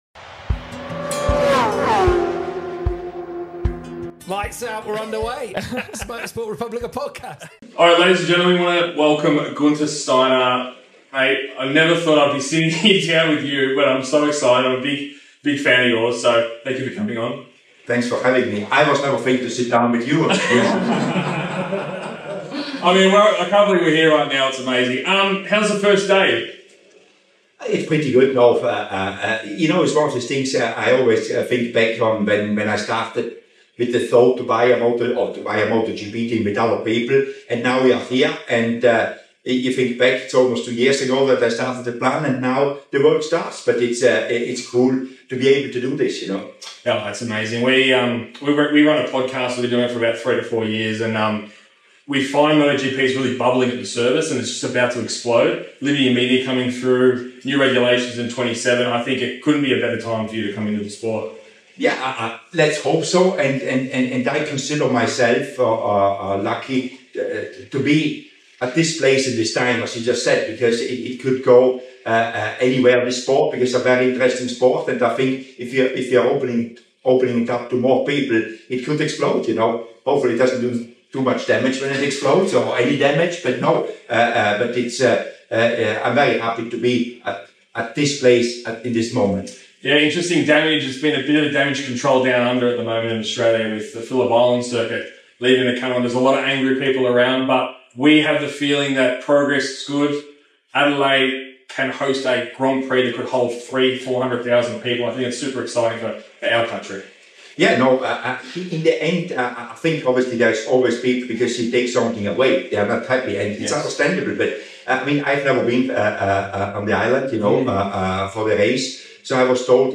Sorry for the audio we had severe technical issues!